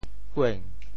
莞 部首拼音 部首 艹 总笔划 10 部外笔划 7 普通话 guān guǎn wǎn 潮州发音 潮州 uêng2 文 guêng2 文 中文解释 莞〈名〉guan (形声。